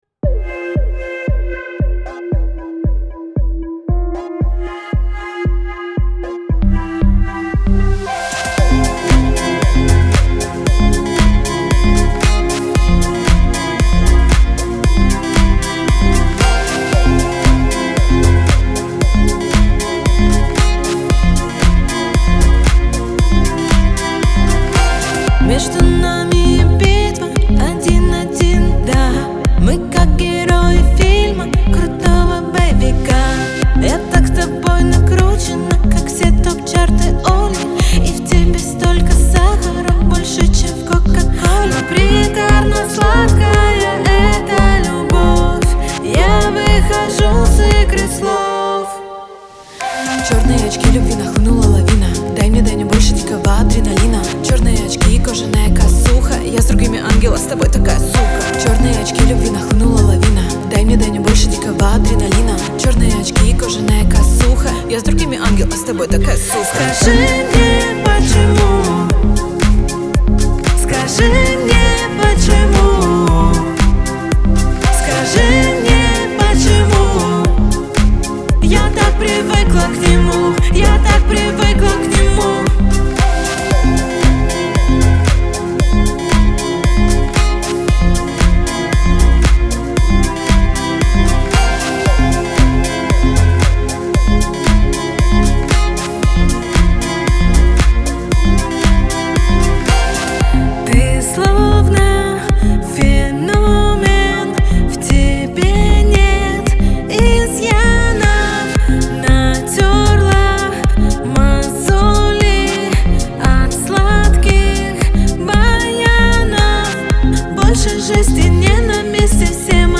динамичными битами и запоминающимися мелодиями